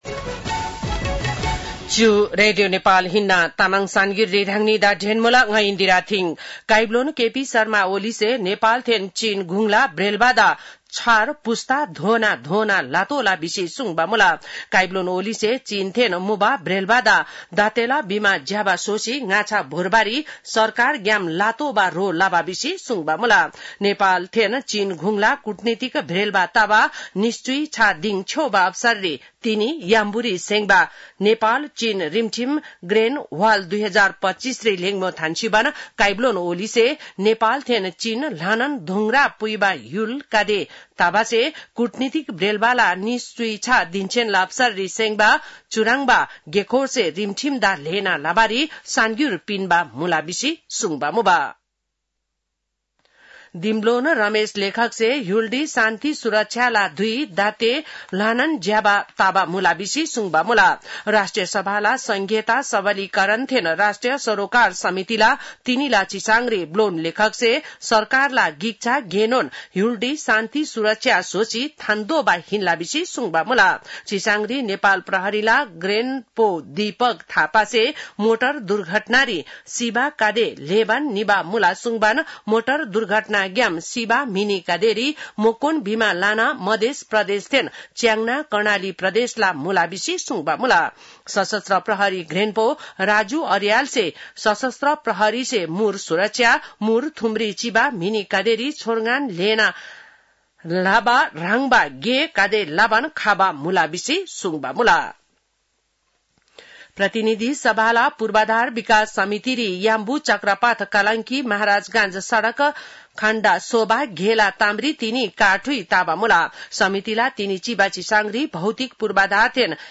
An online outlet of Nepal's national radio broadcaster
तामाङ भाषाको समाचार : २ साउन , २०८२
Tamang-news-4-02.mp3